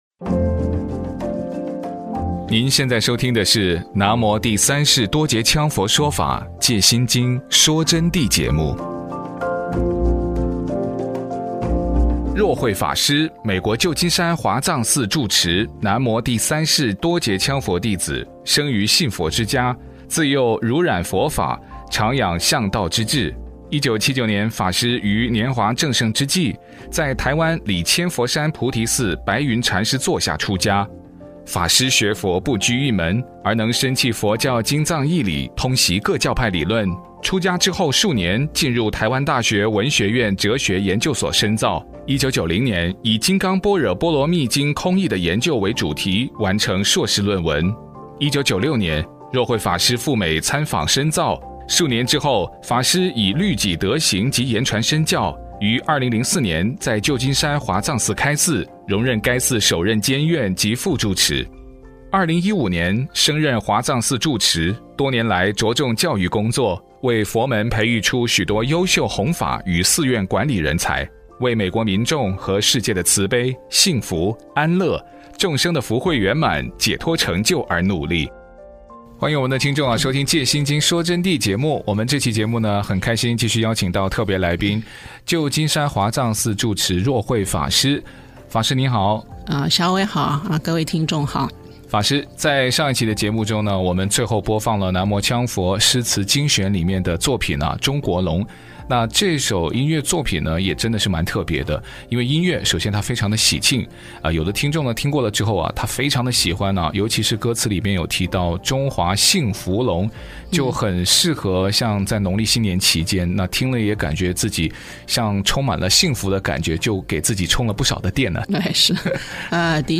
佛弟子訪談（七十九）南無羌佛所說《世法哲言》的緣起與《哲言精選》單曲欣賞